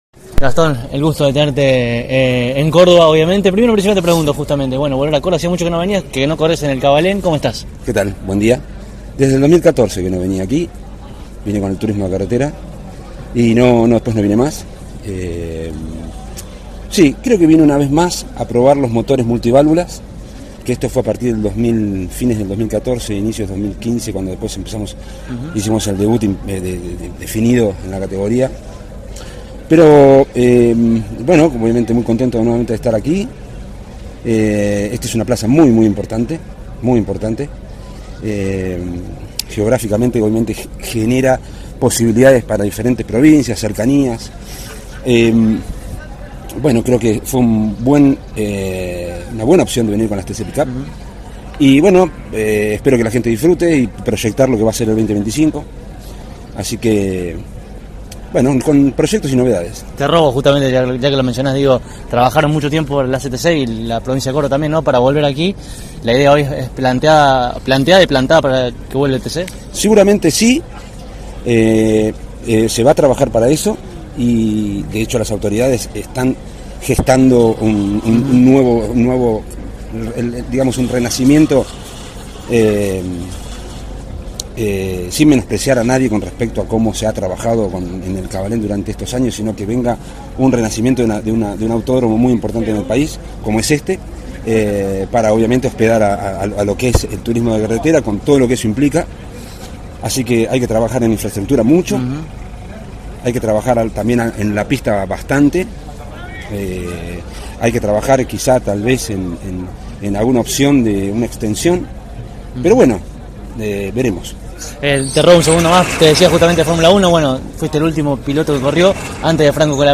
En el marco de la histórica fecha de TC Pick Up en el «Autódromo Oscar Cabalén», Gastón Mazzacane pasó por nuestros micrófonos.